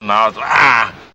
No. It’s the various grunts, straining, and other physical effort that leads to Arnold Schwarzenegger emitting odd noises.
Anyway, here’s an Arnold noise out in the wild.
aagghoo-2.mp3